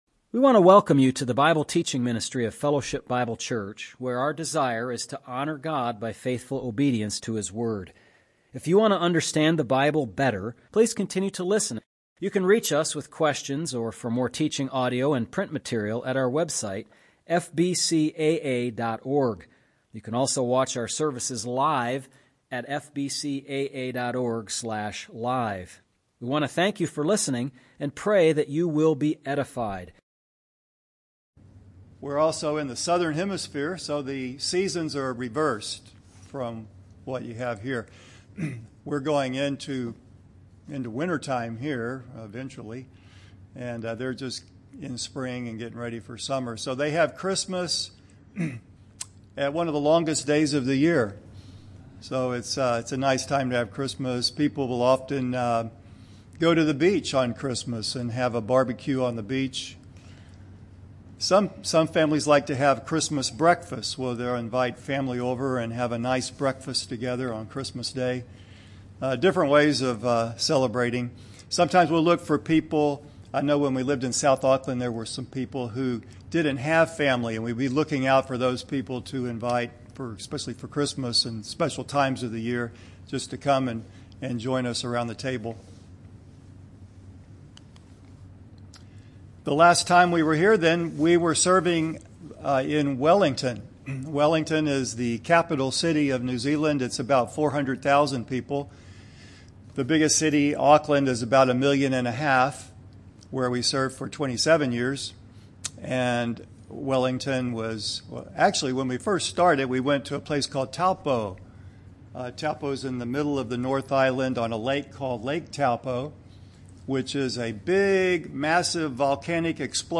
MP3 recordings of sermons and Bible studies for the Sunday ministries at the church.